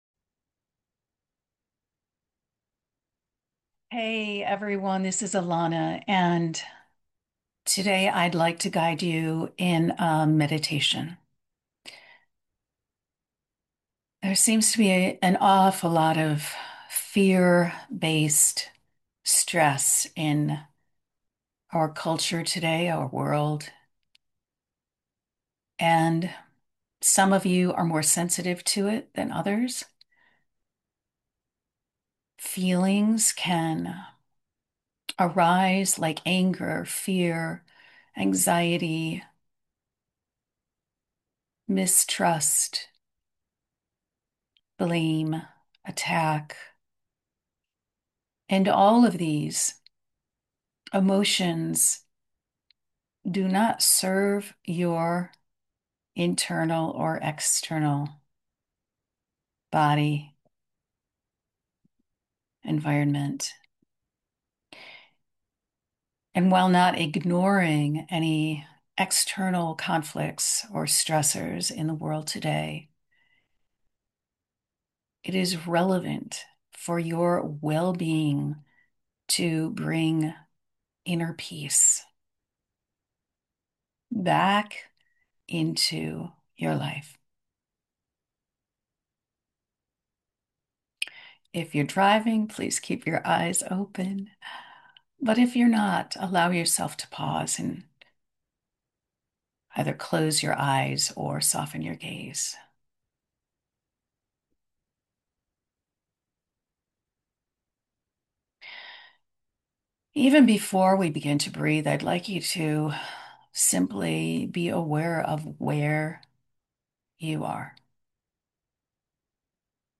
This guided meditation helps listeners: